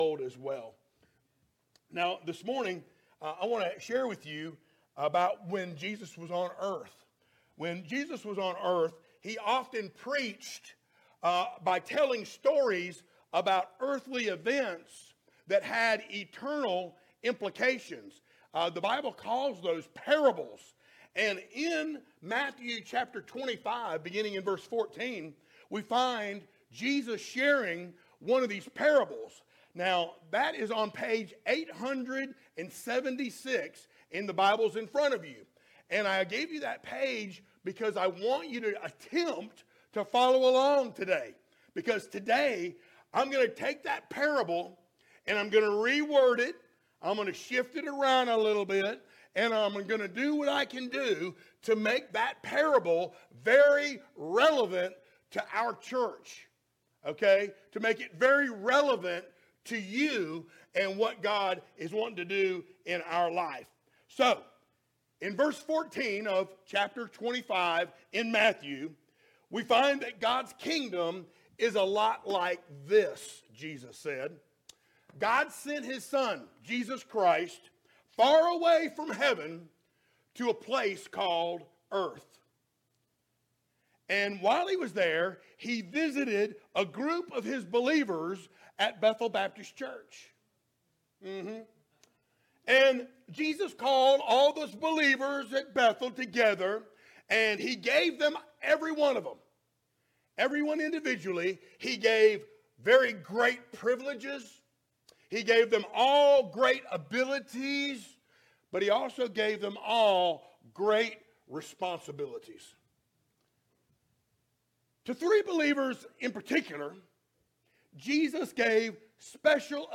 Series: sermons
Matthew 25:14-30 Service Type: Sunday Morning Download Files Notes « Living By Faith Not Sight “Imparting Forgiveness” Getting Real About Revival…